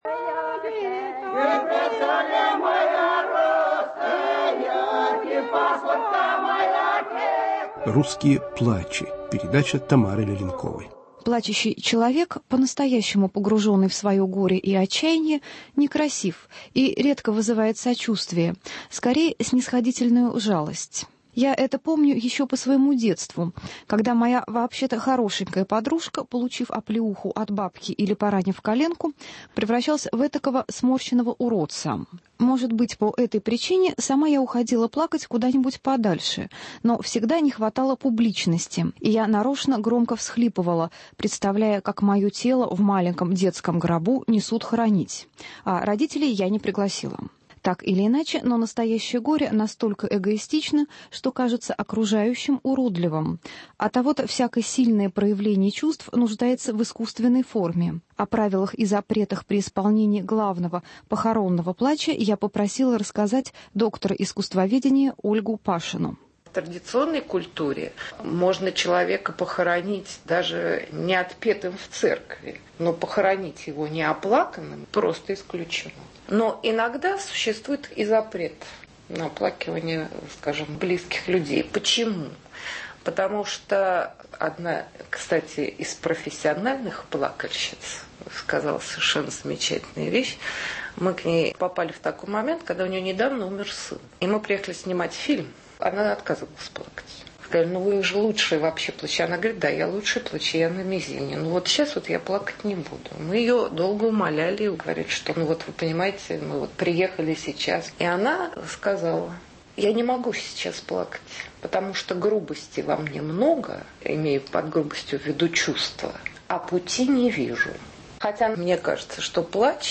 "Русские плачи" - редкие записи, сделанные в глубинке, и комментарий